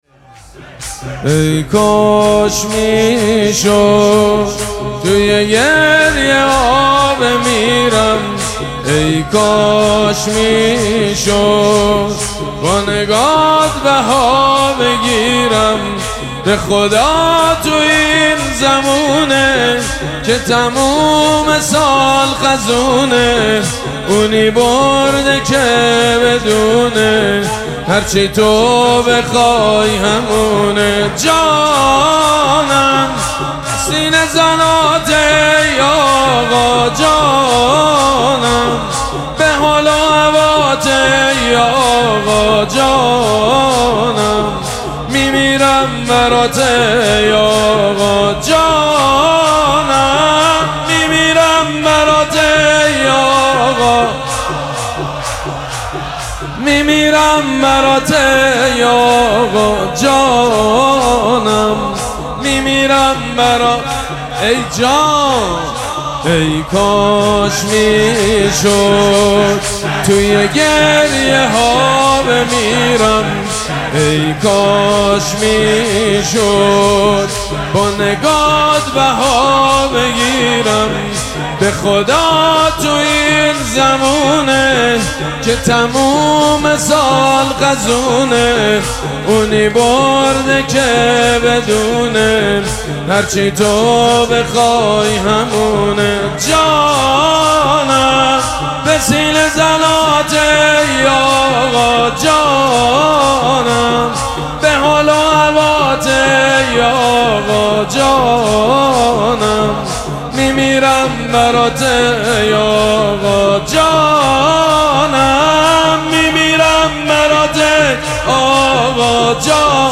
شب پنجم مراسم هشت شب عاشقی ماه رمضان
شور
مداح
حاج سید مجید بنی فاطمه